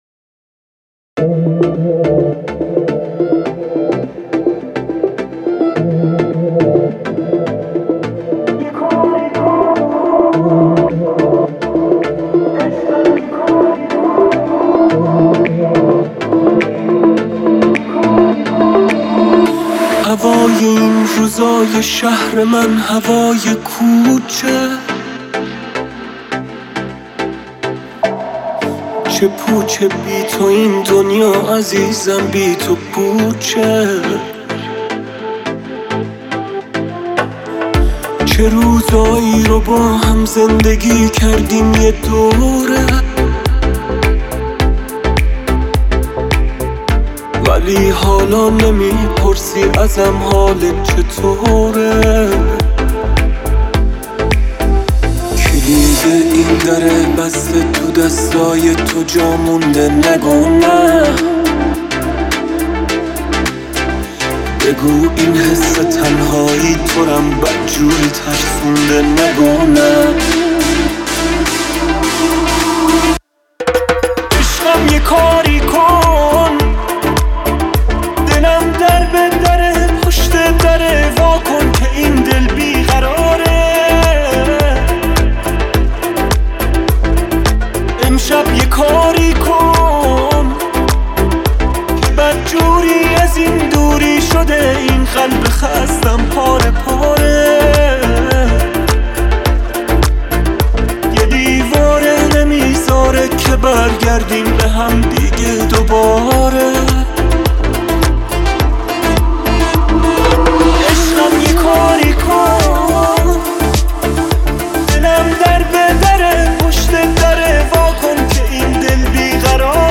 Tropical House Mix